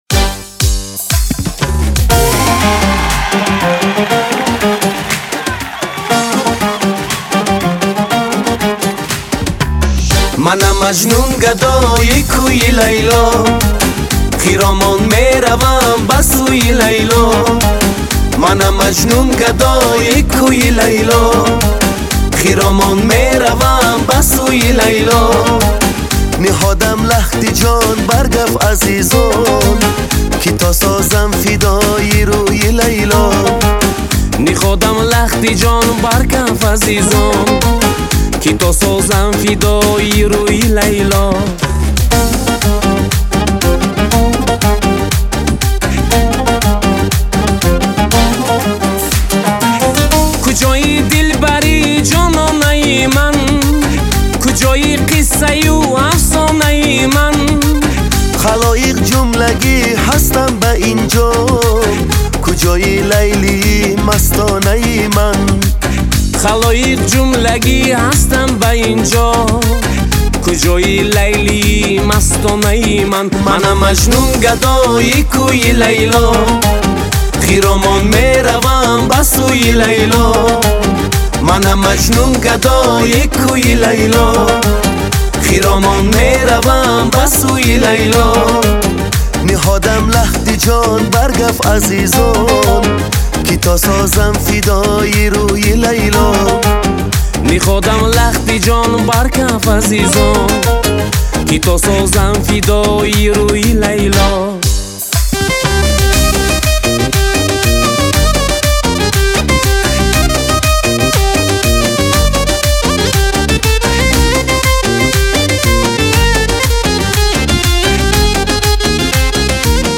Трек размещён в разделе Узбекская музыка / Поп.